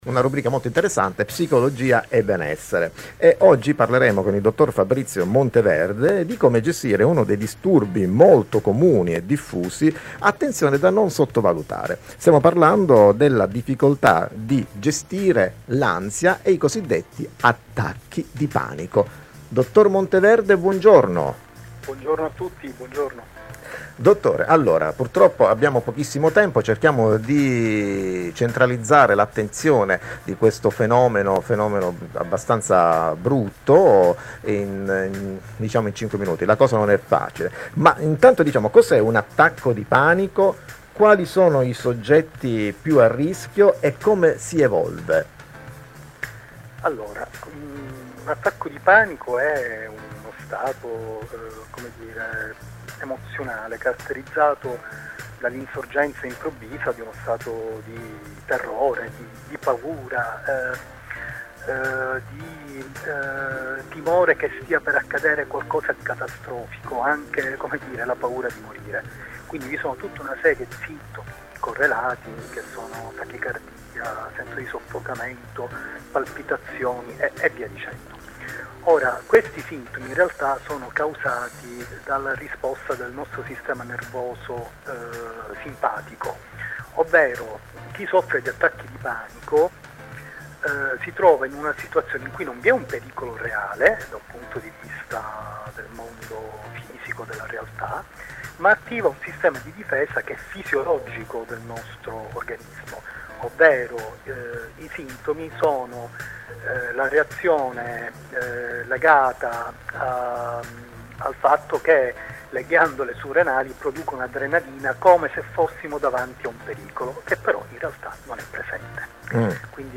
Attacchi di panico e psicoterapia: intervista radiofonica - Dott.